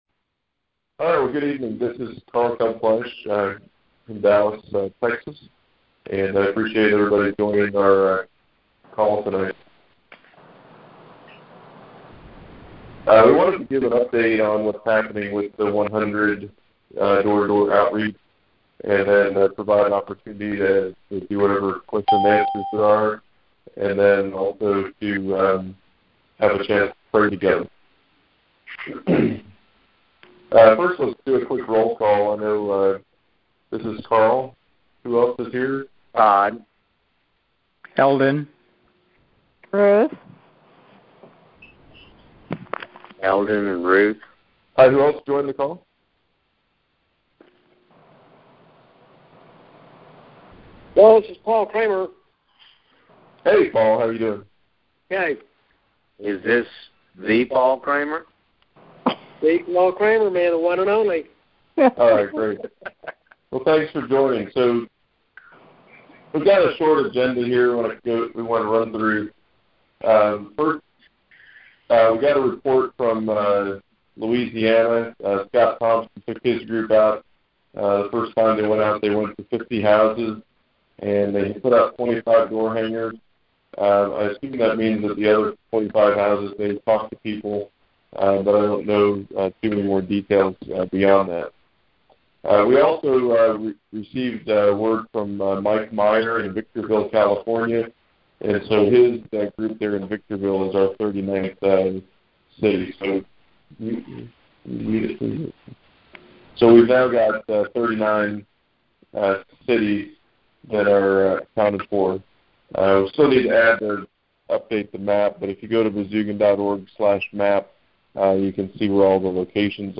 (Colossians 4:2-4) Listen to recordings of conference calls about SPREAD and the 100 Challenge.